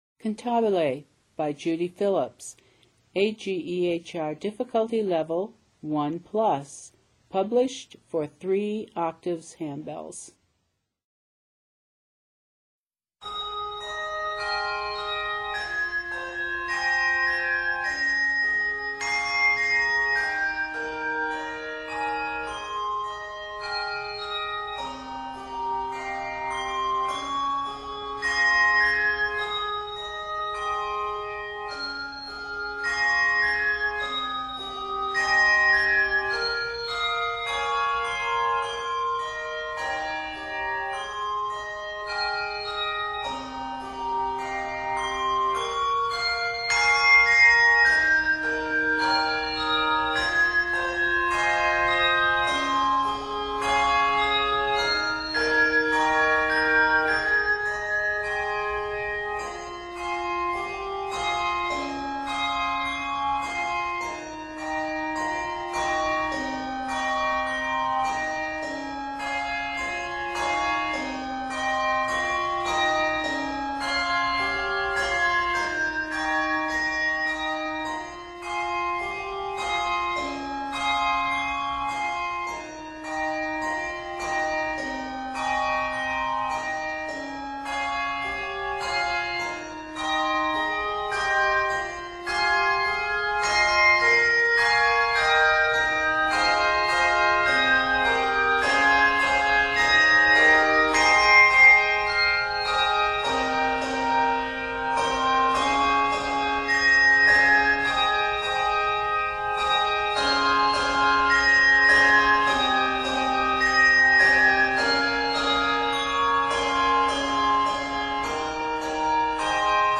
Written in Ab Major, this piece is 59 measures.